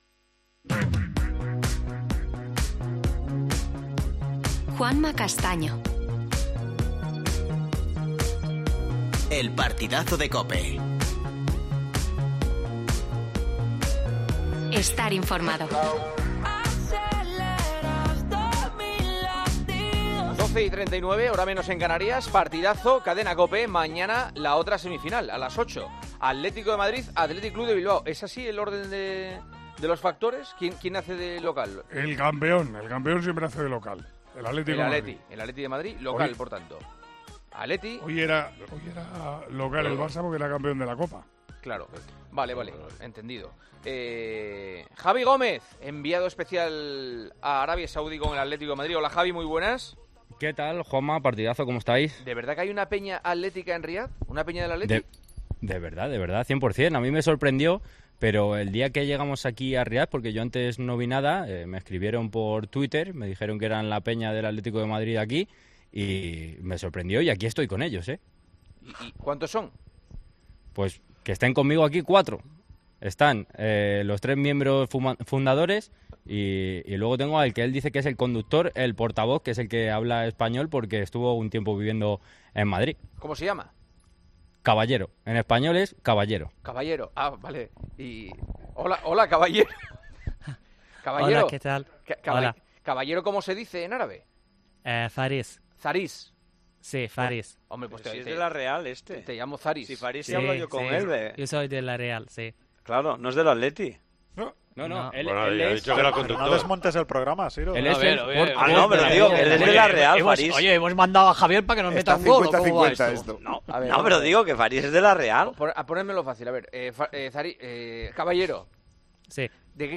La peña atlética saudí canta en El Partidazo de COPE el himno rojiblanco